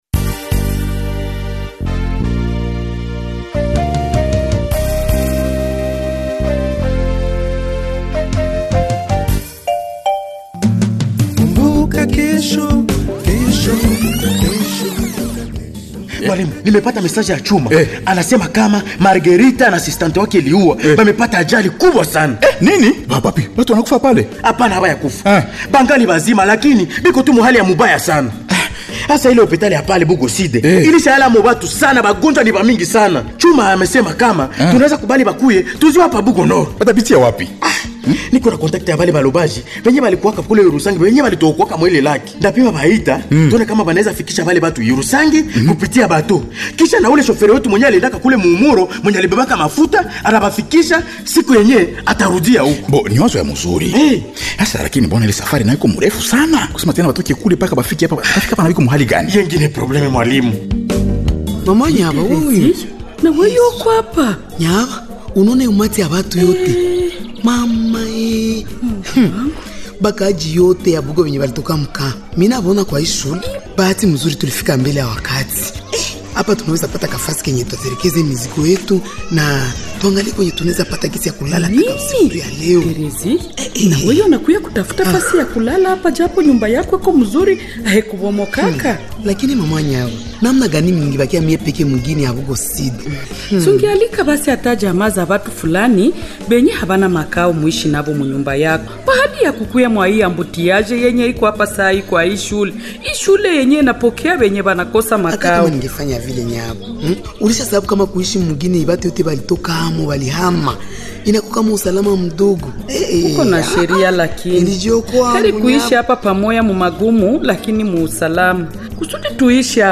Voici le 974e numéro du feuilleton Kumbuka Kesho du 16 au 22 février 2026